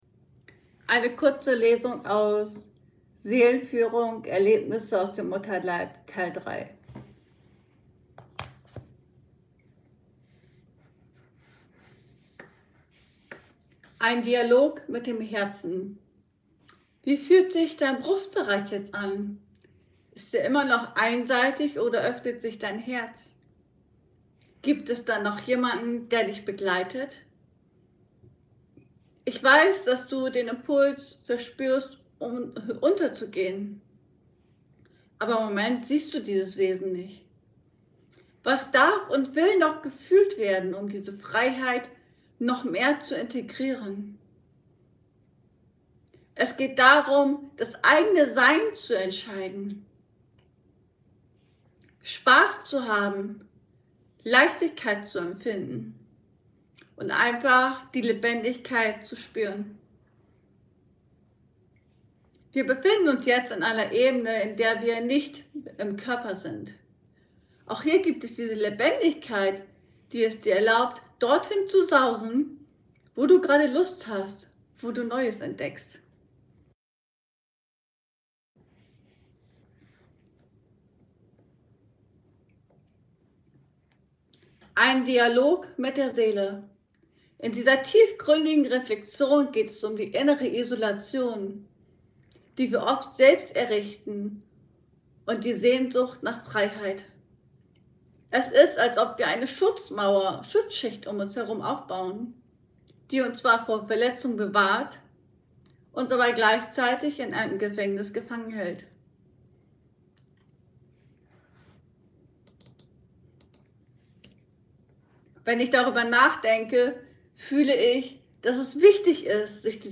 Lesung: Seelenführung - Erlebnisse aus dem Mutterleib Teil 3 ~ Ankommen lassen Podcast